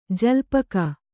♪ jalpaka